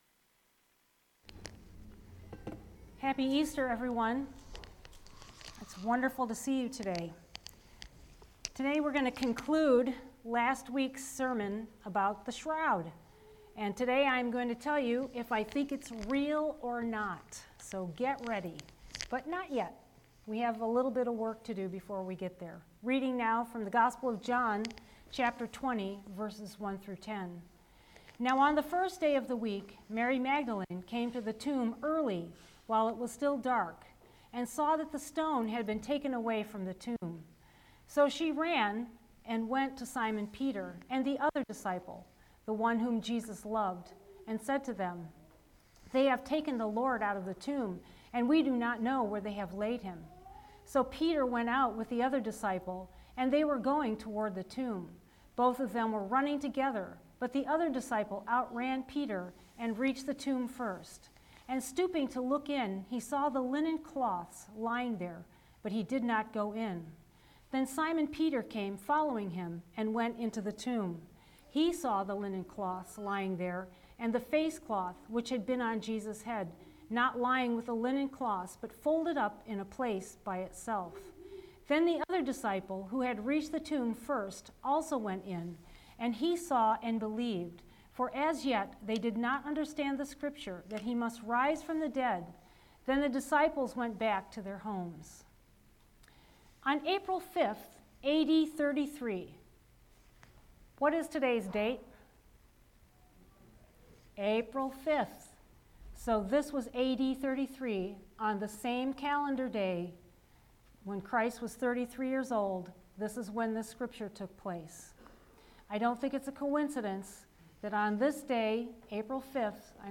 The Shroud – The Answer – Faith Wesleyan Church